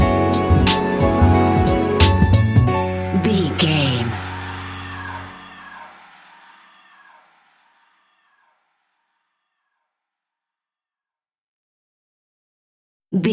Ionian/Major
F♯
laid back
Lounge
new age
chilled electronica
ambient